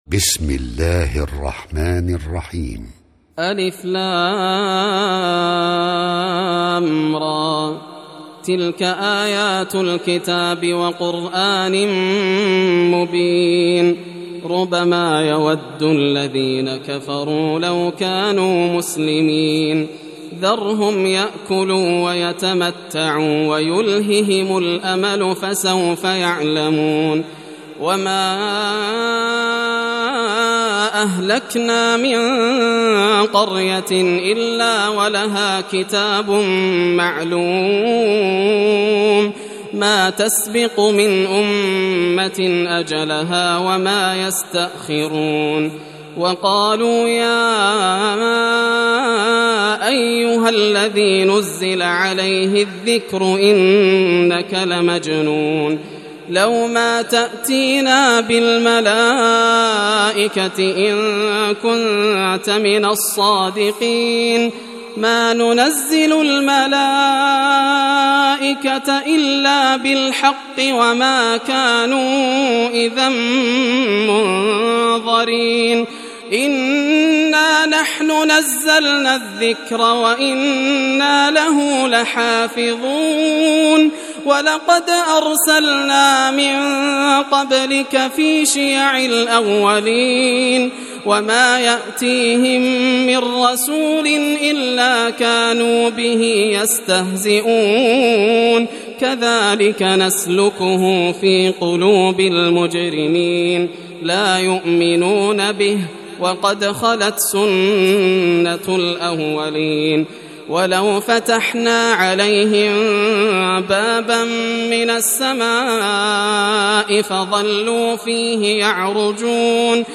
سورة الحجر > المصحف المرتل للشيخ ياسر الدوسري > المصحف - تلاوات الحرمين